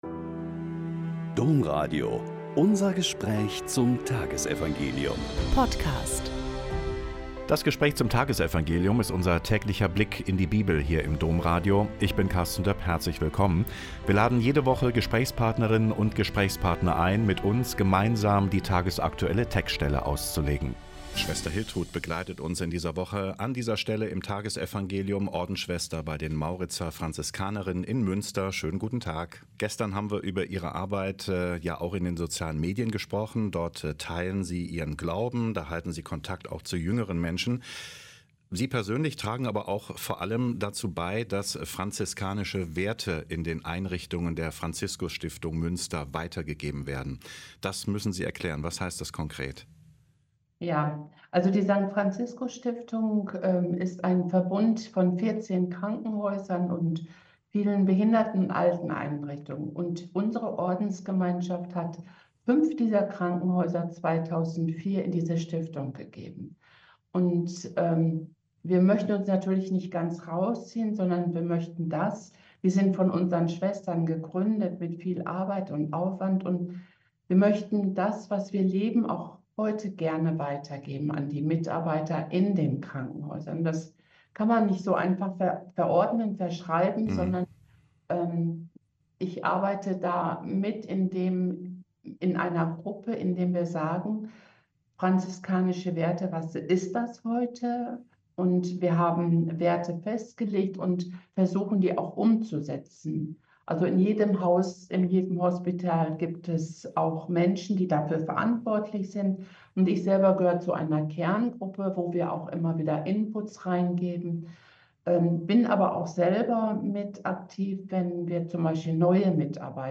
Joh 8,51-59 - Gespräch